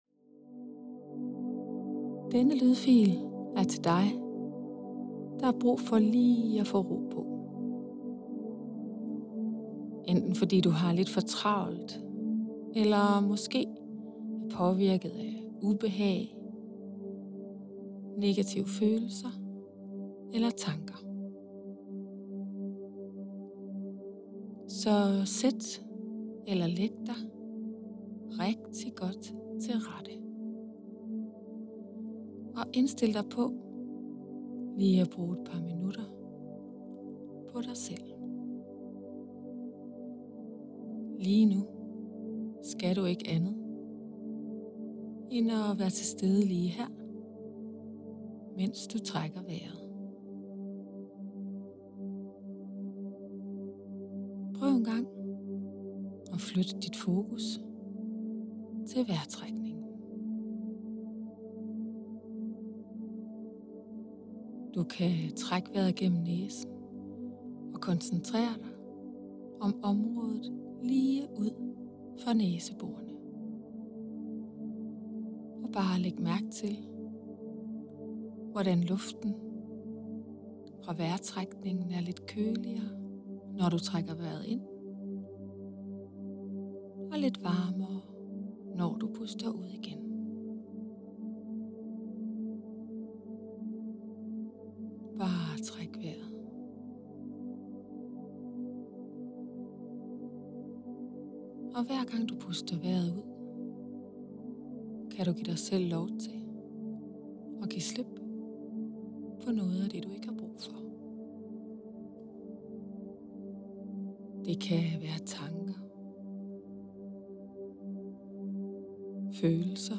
Hypnose til graviditet og fødsel
Et øjebliks ro - med musik
Et-Oejebliks-Ro-Paa-V1-med-musik.mp3